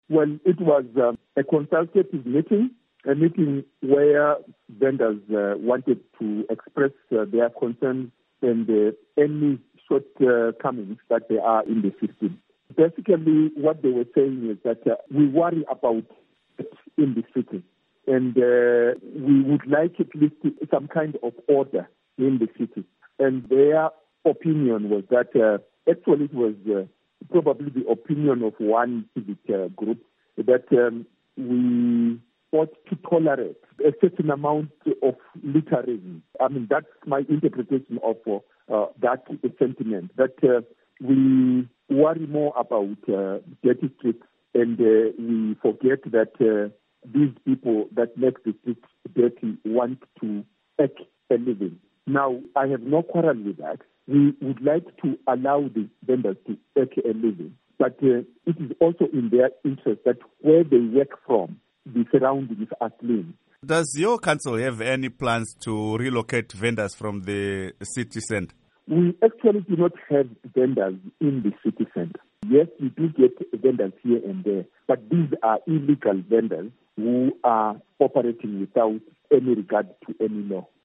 Interview With Martin Moyo